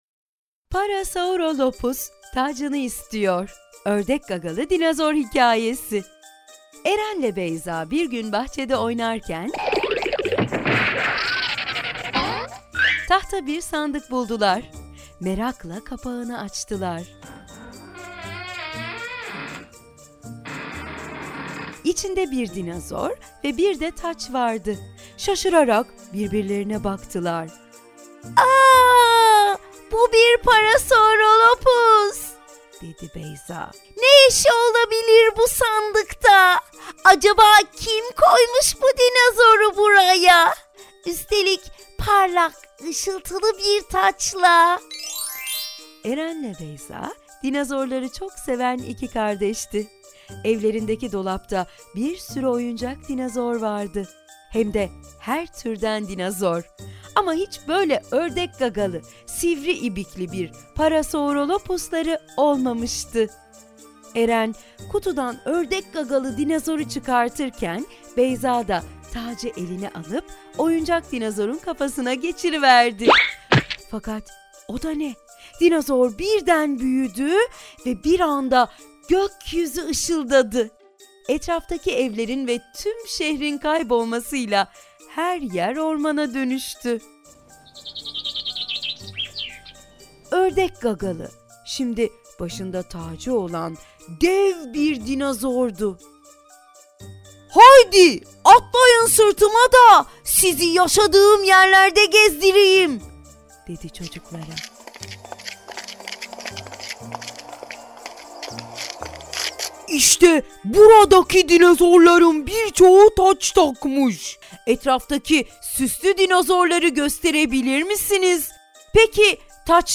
Sesli Kitap